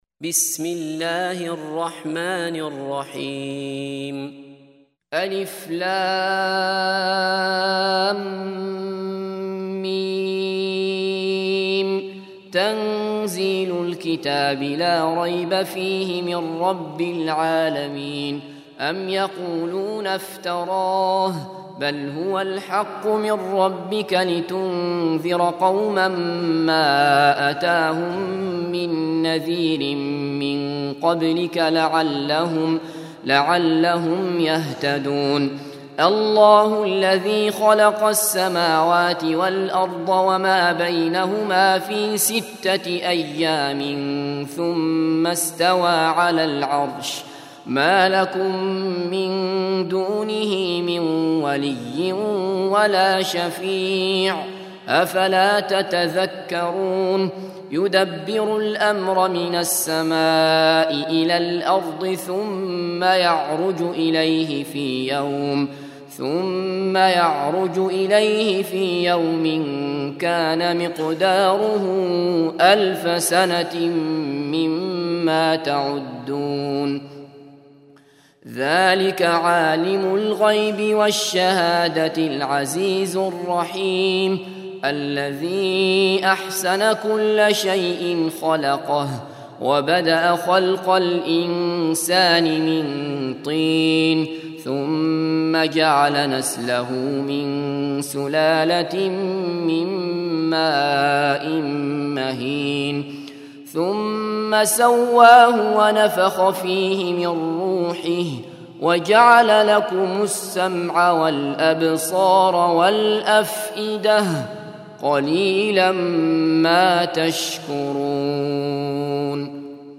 32. Surah As�Sajdah سورة السجدة Audio Quran Tarteel Recitation
Surah Repeating تكرار السورة Download Surah حمّل السورة Reciting Murattalah Audio for 32.